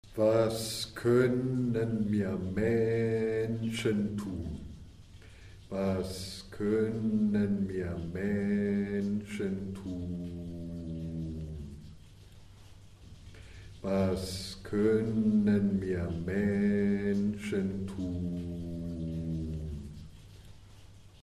Prononciation
Was konnen mir Menschen tun - chant.mp3